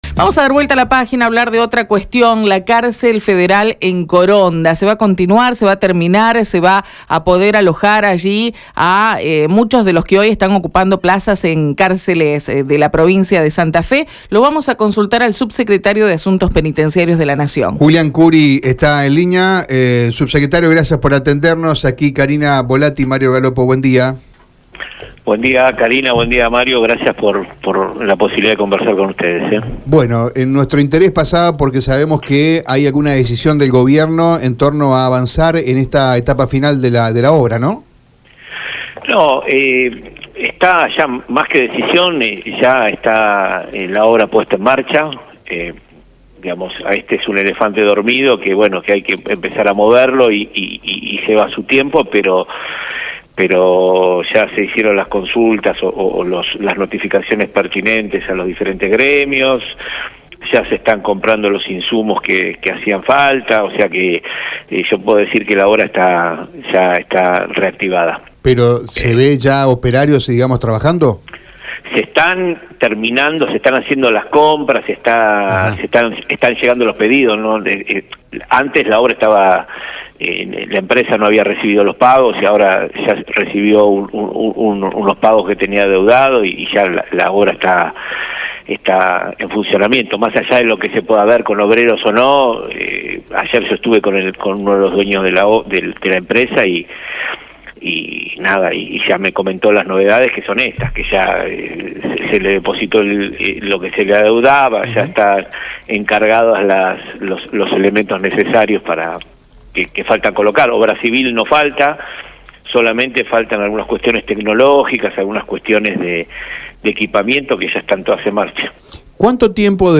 La cárcel federal que albergará unos 500 presos en Coronda, tiene fecha de finalización. El subsecretario de Asuntos Penitenciarios, Julián Curi lo confirmó en Radio EME y dijo que se trata del cumplimiento de la gestión del presidente Javier Milei y la ministra de Seguridad, Patricia Bullrich.